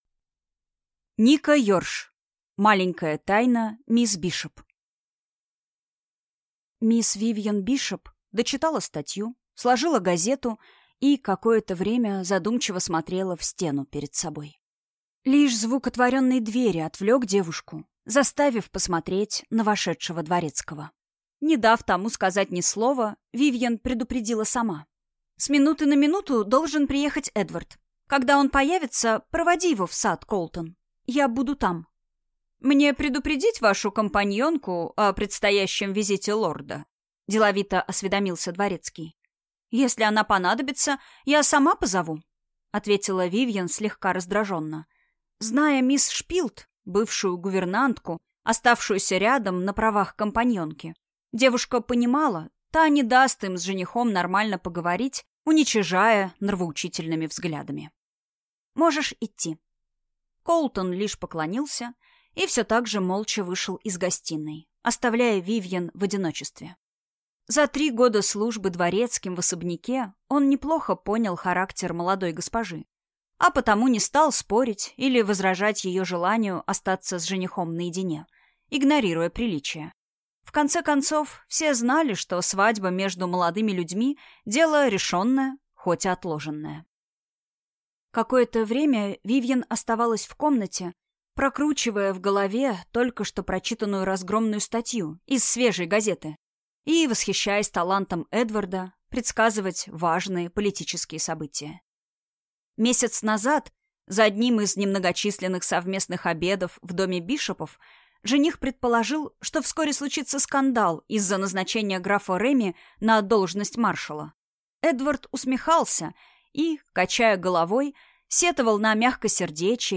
Аудиокнига Маленькая тайна мисс Бишоп | Библиотека аудиокниг